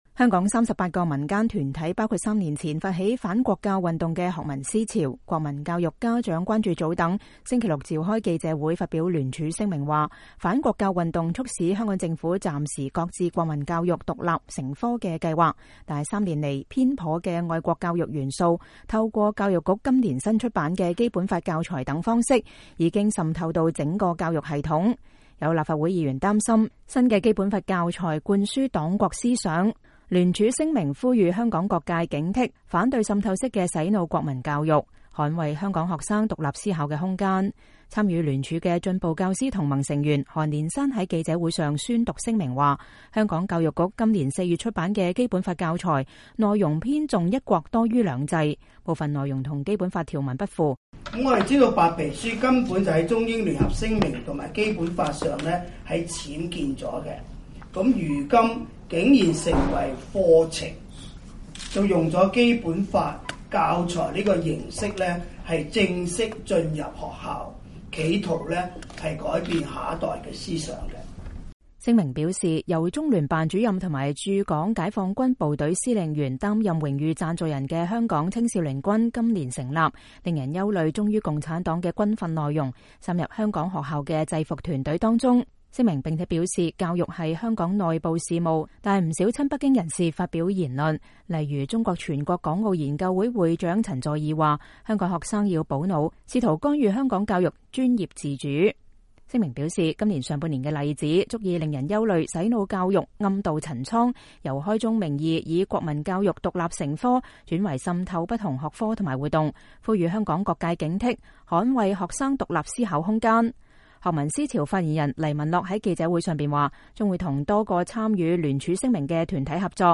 公民黨立法會議員、香港浸會大學政治及國際關係副教授陳家洛在記者會上表示，香港教育局今年出版的基本法教材是一份政治文宣，灌輸黨國思想。